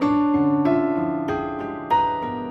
Index of /musicradar/gangster-sting-samples/95bpm Loops
GS_Piano_95-D2.wav